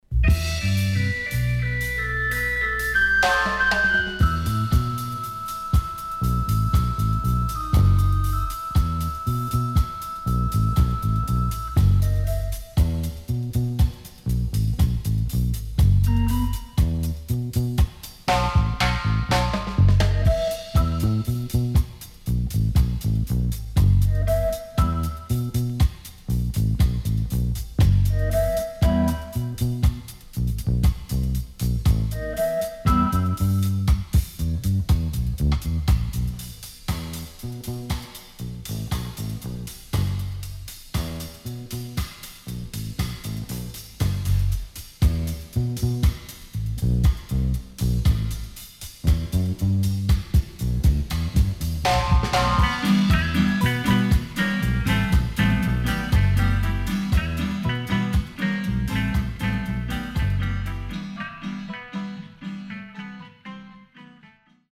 Great Inst.Ska Of Roots Band.W-Side Good